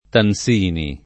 [ tan S& ni ]